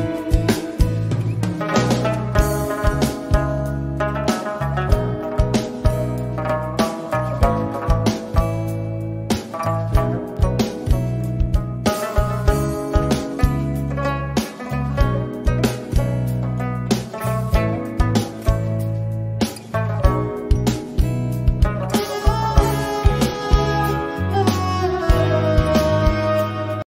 Guitar Ringtones
Instrumental Ringtones
Romantic Ringtones